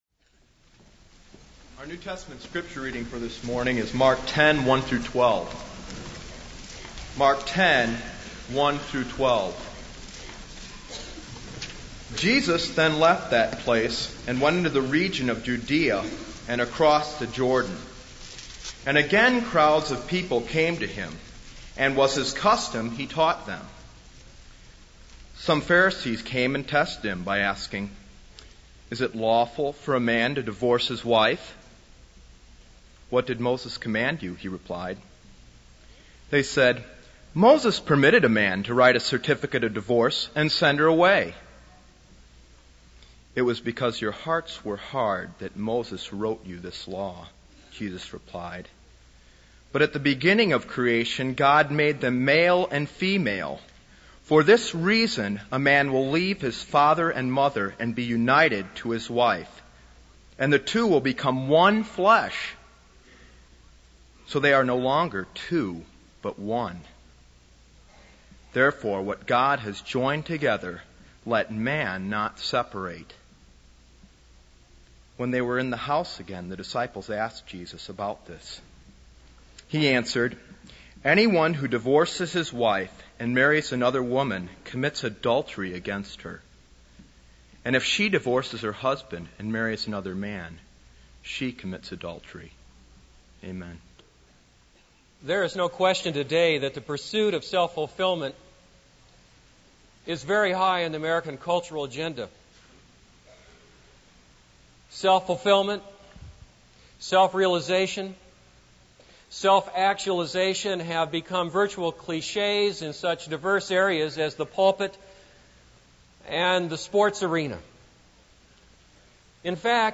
This is a sermon on Mark 10:1-12.